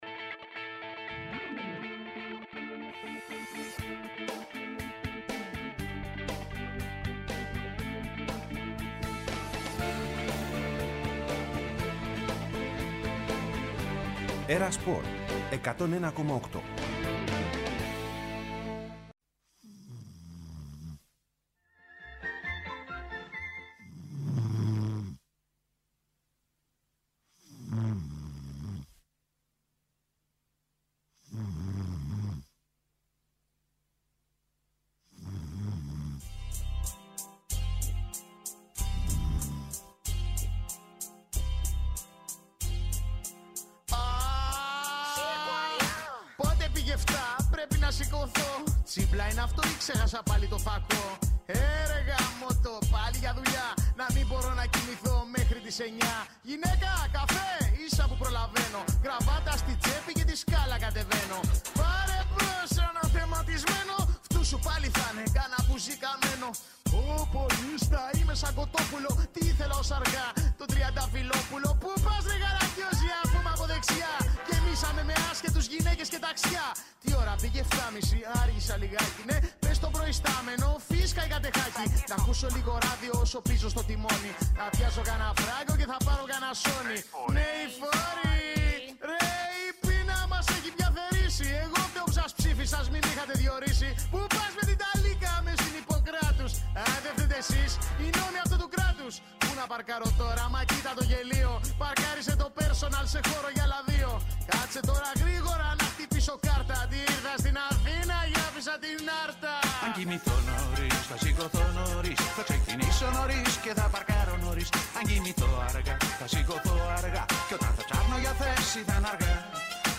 Συνεντεύξεις και ρεπορτάζ για όσα συμβαίνουν εντός και εκτός γηπέδων.